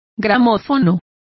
Complete with pronunciation of the translation of gramophone.